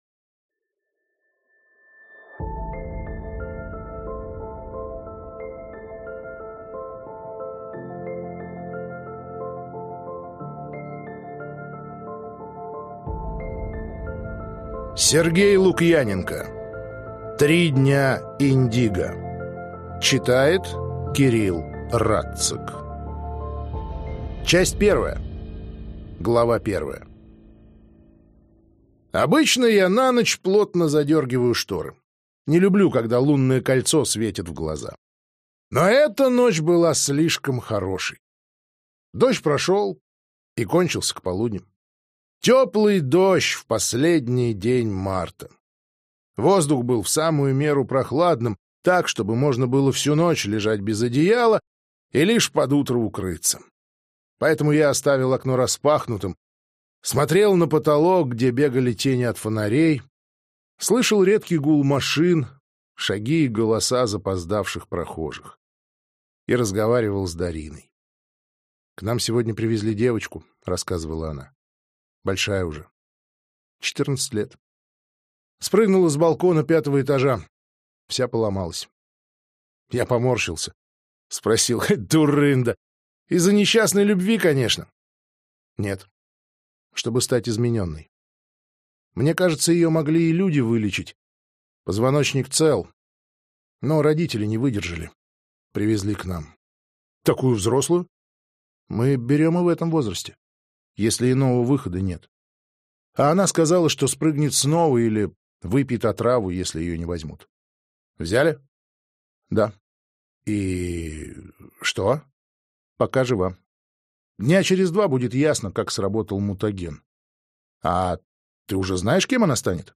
Аудиокнига Три дня Индиго | Библиотека аудиокниг